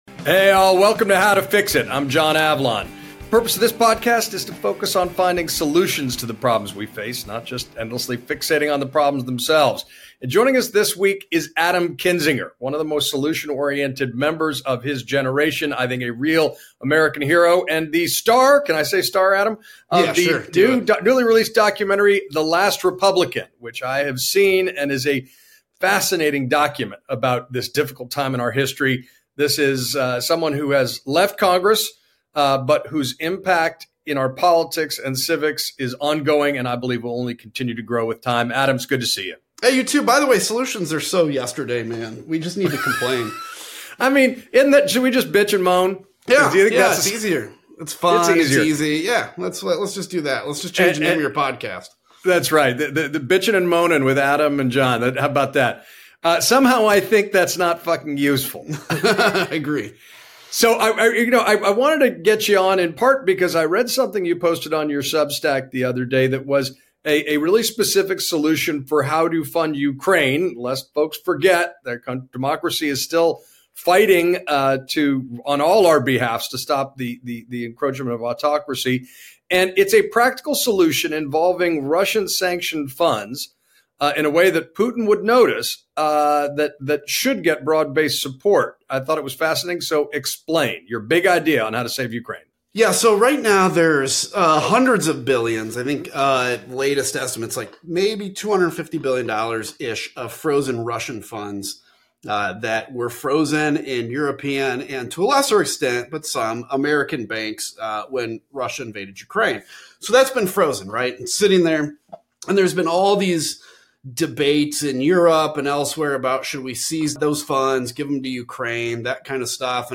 Former congressman and January 6 Committee member Adam Kinzinger joins John Avlon to talk about fixing what’s broken, from using Russia’s own frozen cash to rebuild Ukraine, to confronting Trump-era corruption, to why Democrats have to “fight fire with fire” on redistricting.